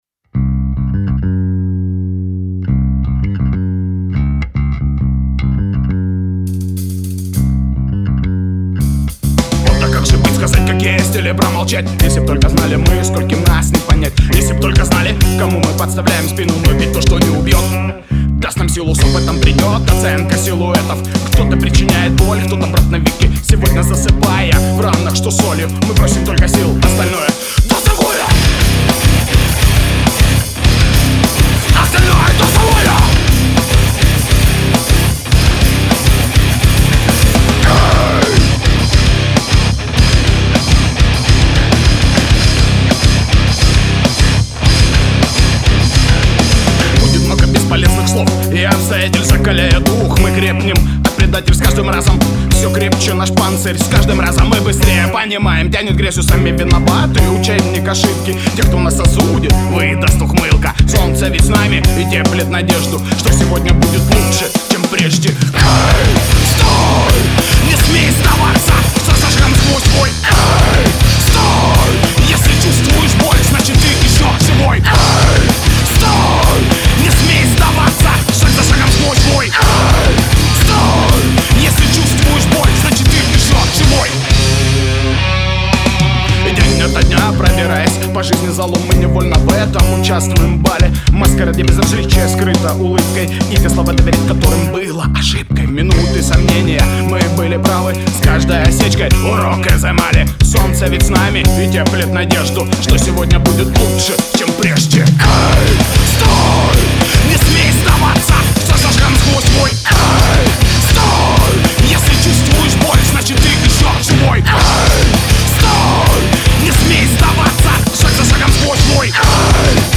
Как же быть / Rapcore
Очередные потуги в записи и сведении тяжелека.